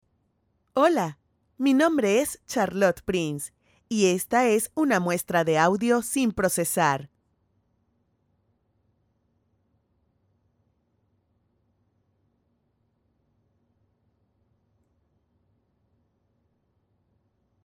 Female
Character, Confident, Conversational, Corporate, Friendly, Natural, Young
Microphone: Audio-Technica AT4030a Cardioid Condenser Microphone